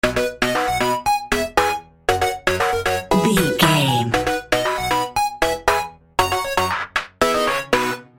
Ionian/Major
bouncy
bright
cheerful/happy
funky
groovy
lively
playful
uplifting
synthesiser
drum machine
80s